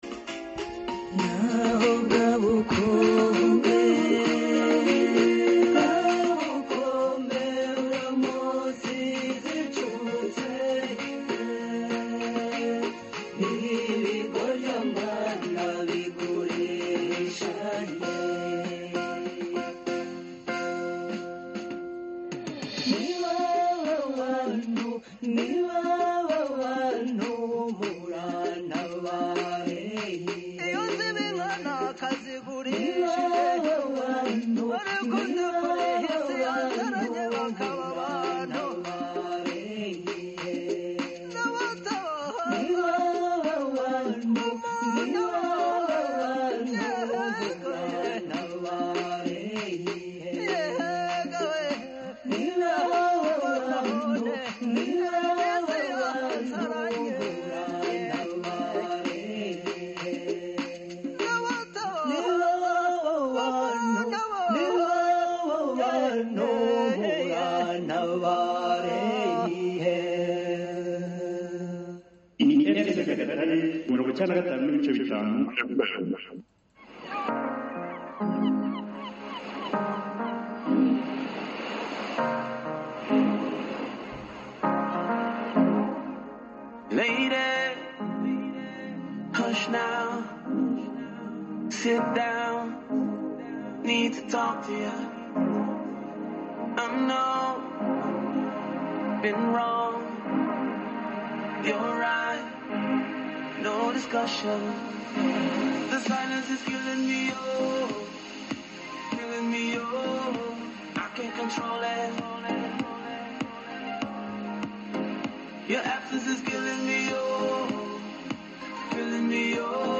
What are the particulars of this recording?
Live radio talk shows were conducted to engage the target audience for feedback on attitudes, behavior changes, raising awareness on SEA. Nearly 600 people participated in the talk shows through phone calls and social media.